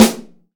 Index of /90_sSampleCDs/AKAI S6000 CD-ROM - Volume 3/Snare1/PICCOLO_SN